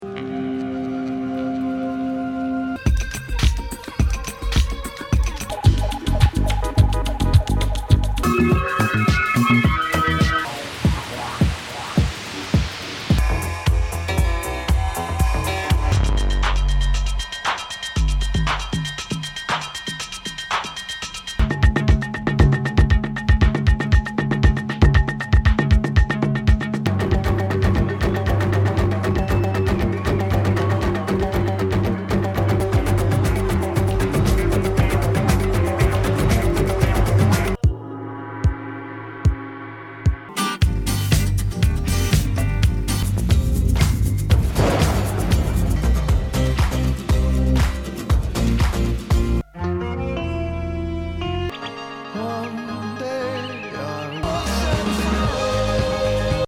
縦横無尽にサイケデリック感覚渦巻くハウス、テクノ、ディスコダブ、リエデット
試聴は全曲ダイジェスト版です。